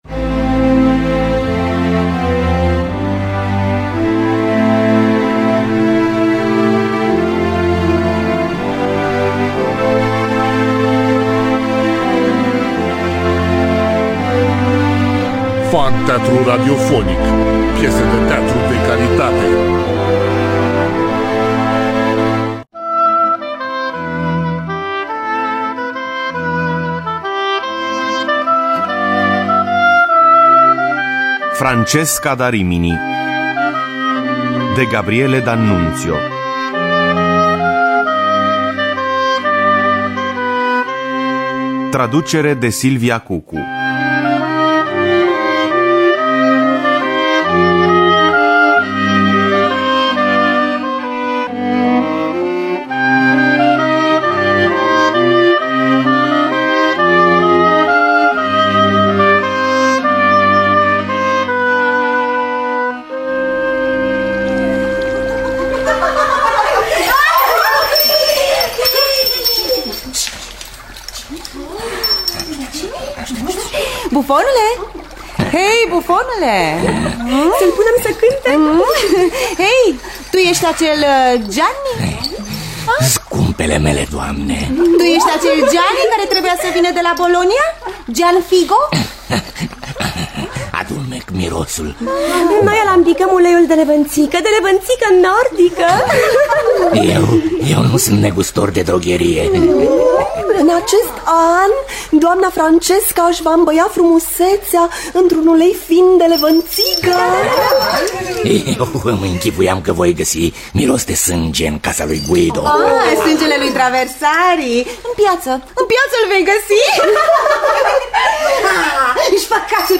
Francesca da Rimini de Gabriele d’Annuzio – Teatru Radiofonic Online